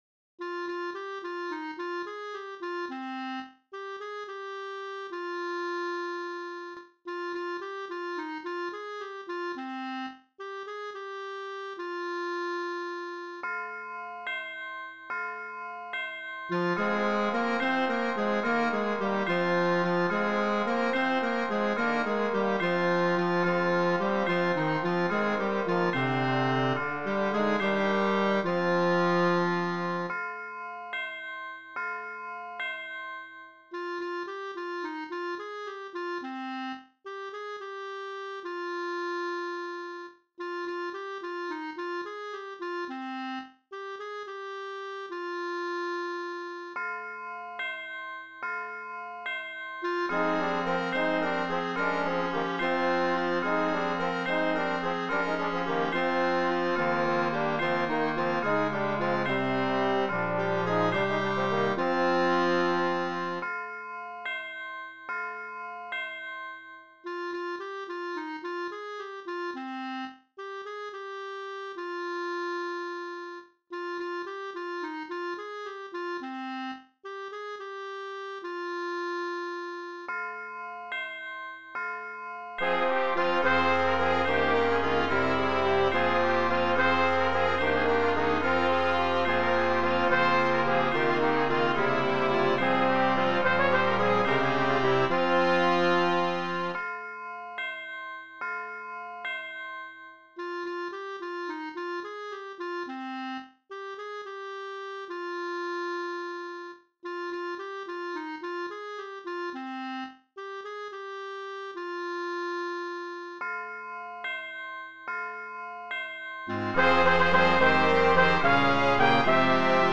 Voicing: SATB Choir